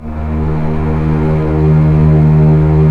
Index of /90_sSampleCDs/Roland - String Master Series/STR_Cbs Arco/STR_Cbs2 Orchest